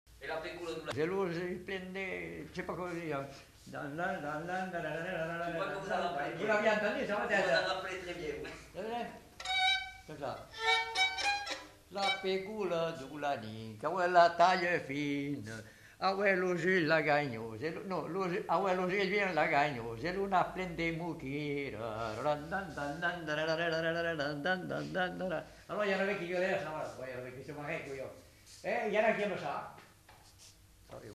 Genre : chant
Effectif : 1
Type de voix : voix d'homme
Production du son : chanté ; fredonné
Danse : congo